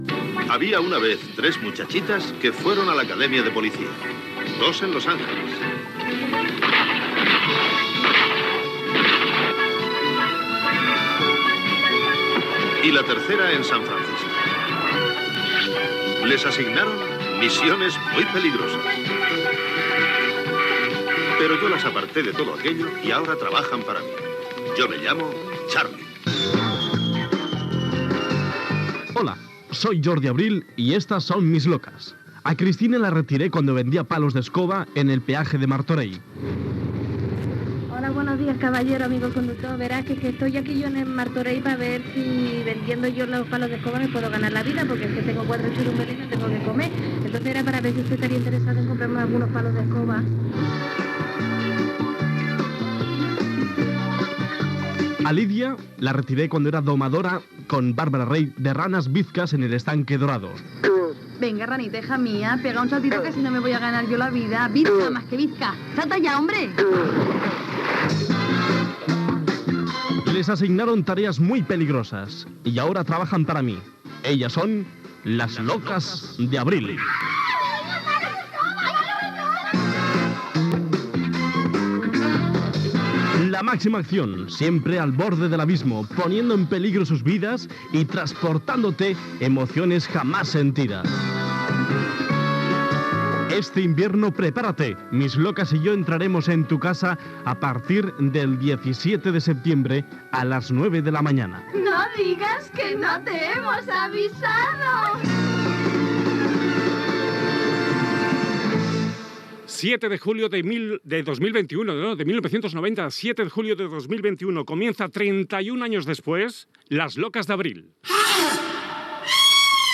Entrevista a Jorge Javier Vázquez que hi va col·laborar.
Gènere radiofònic Entreteniment